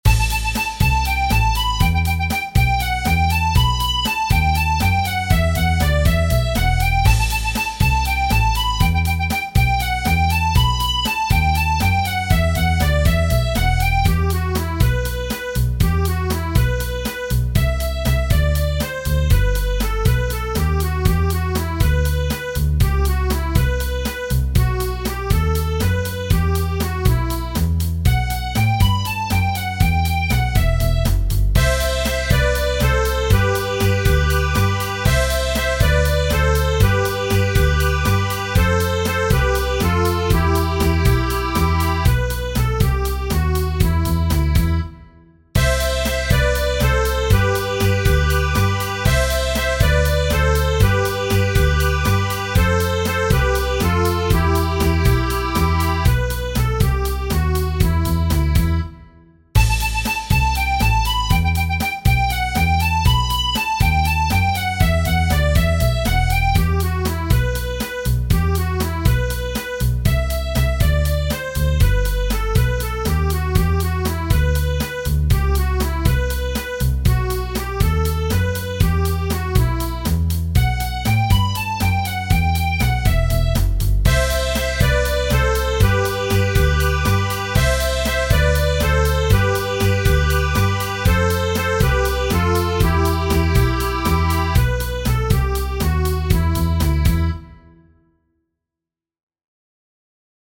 Tradizionale Genere: Folk "Ako umram il zaginam" (in italiano: se io muoio o perisco) è una celebre canzone appartenente al repertorio folk della Macedonia.